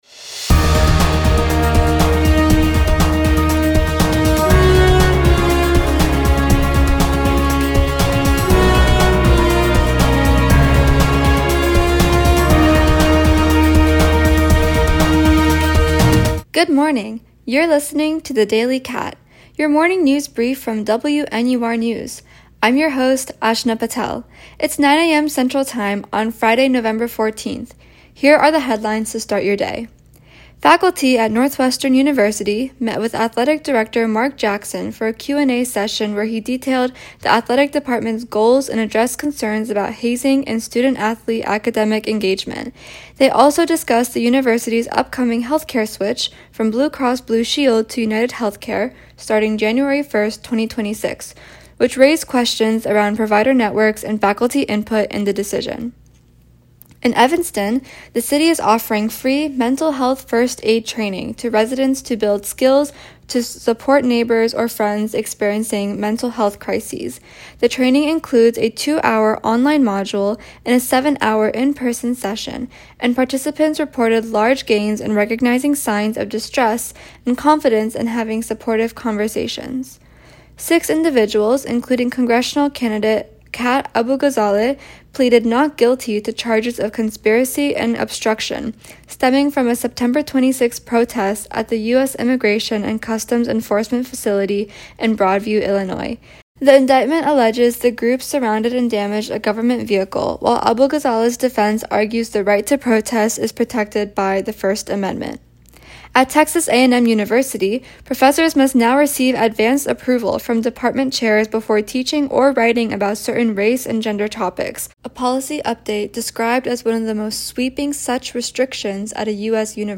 November 14, 2025: Northwestern’s faculty meeting and health-care changes, Evanston’s mental-health training program, federal charges from a Broadview ICE protest, new race and gender teaching restrictions at Texas A&M, and Russian airstrikes on Ukrainian energy infrastructure. WNUR News broadcasts live at 6 pm CST on Mondays, Wednesdays, and Fridays on WNUR 89.3 FM.